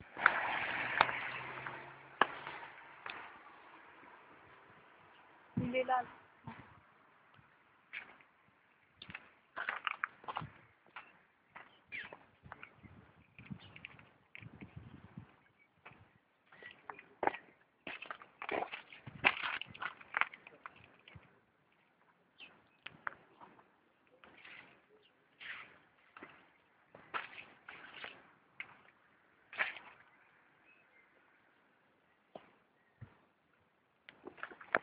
Voitures qui passent, personnes qui parlent